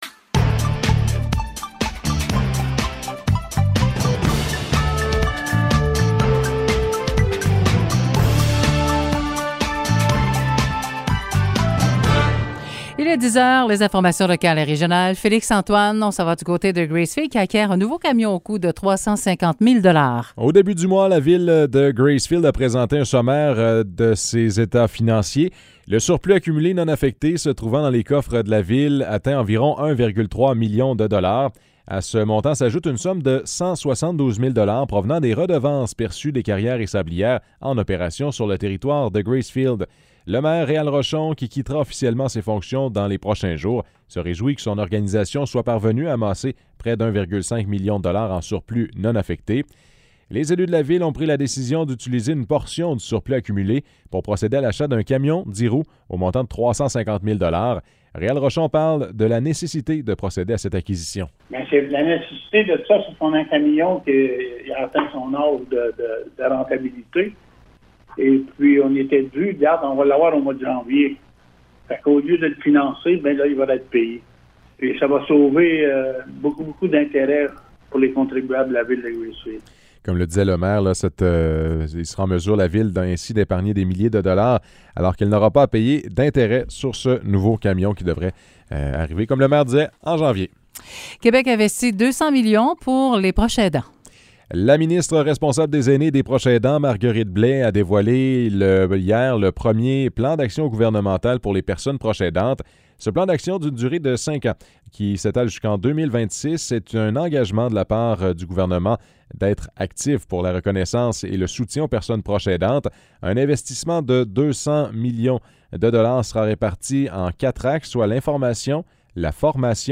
Nouvelles locales - 28 octobre 2021 - 10 h